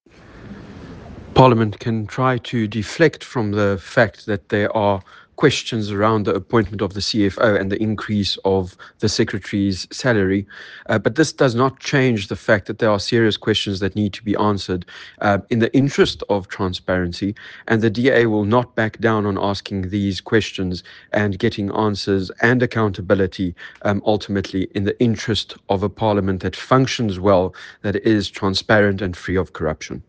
Issued by George Michalakis MP – DA Chief Whip in Parliament